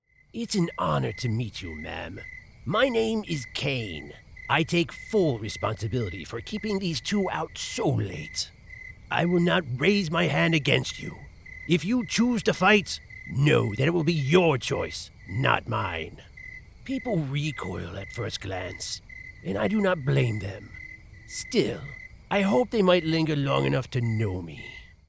Video Game Robotnik_mixdown.mp3
Creepy video game villain